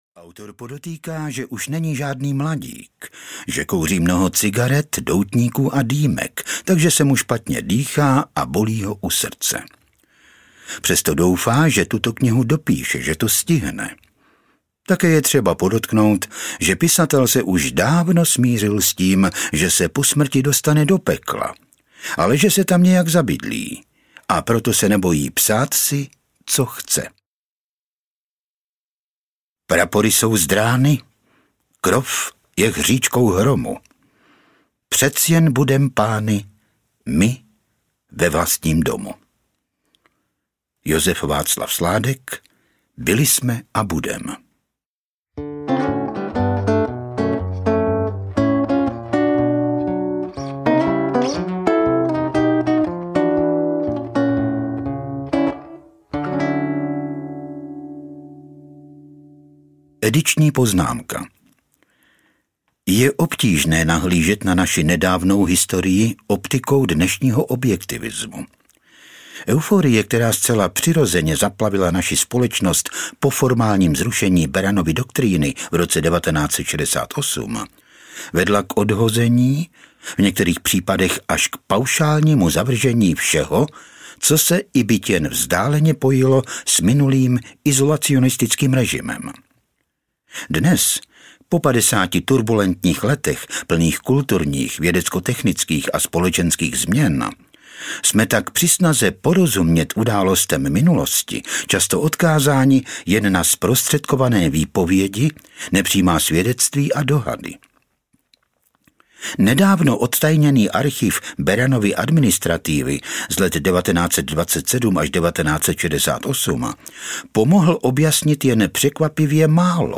Verschluss audiokniha
Ukázka z knihy